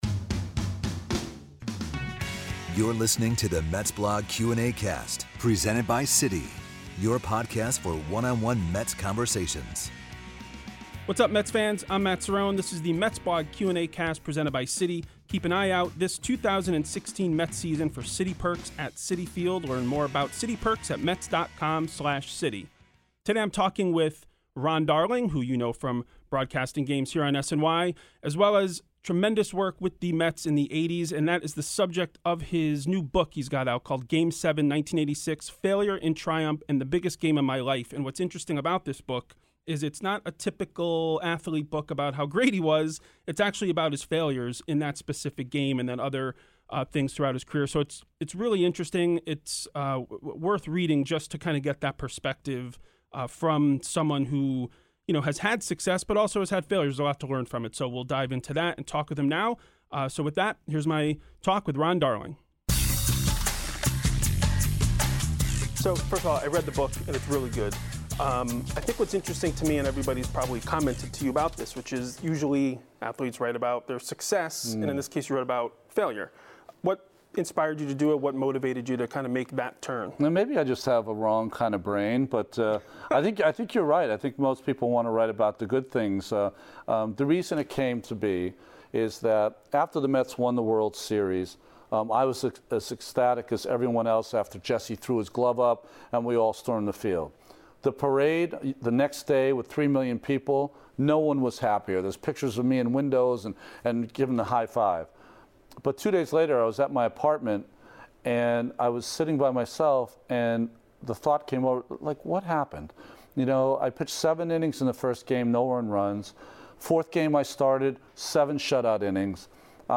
sits down with SNY broadcaster and now author, Ron Darling, to discuss his book